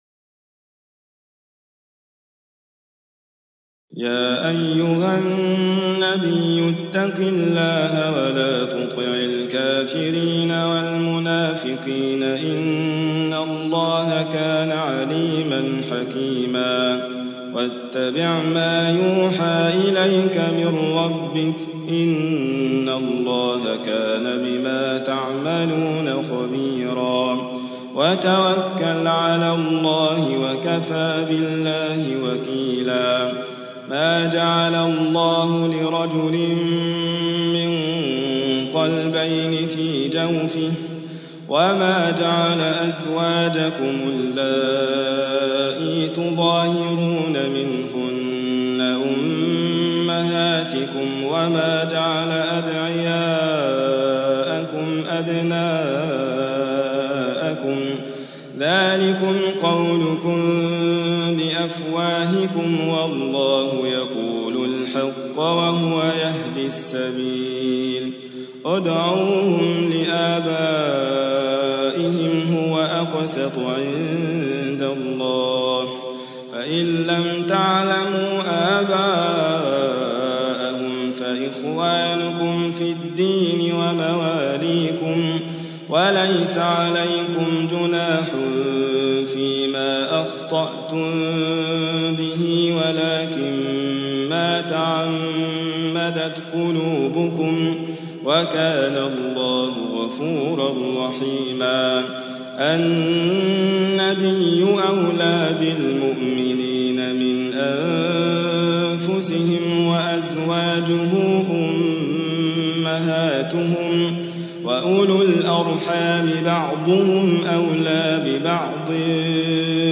33. سورة الأحزاب / القارئ